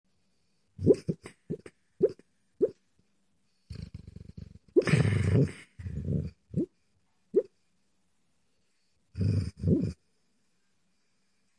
Dog Snoring Sound Button - Free Download & Play